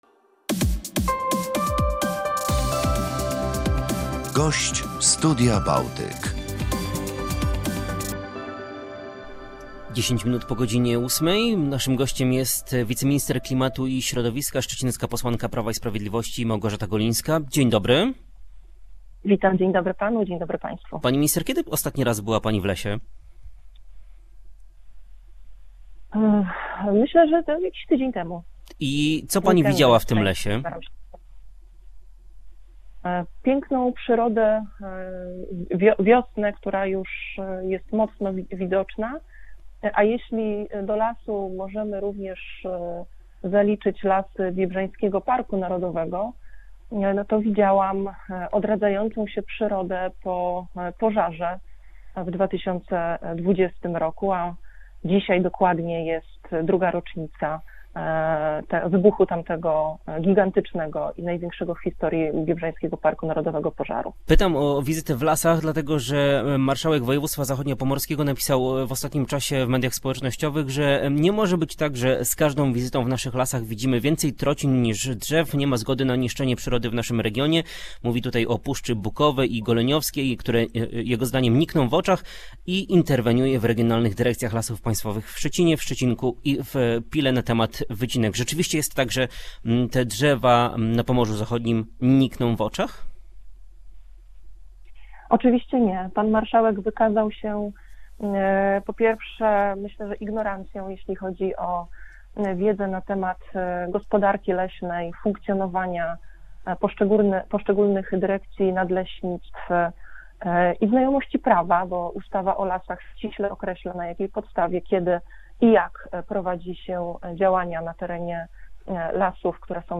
Jaka jest sytuacja w polskich lasach i na jakim etapie znajduje się ustawa o parkach narodowych oraz czy możliwe jest ukaranie Rosji za niszczenie ukraińskiego środowiska naturalnego - to tematy poruszone w porannej rozmowie „Studia Bałtyk”